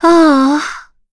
Yuria-Vox-Deny2_kr.wav